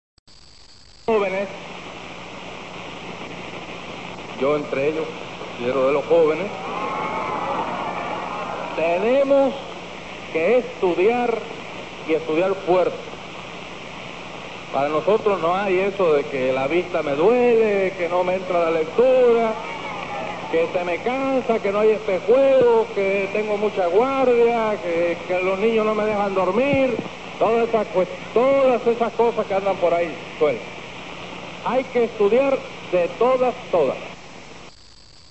Che speaks to the Cuban youth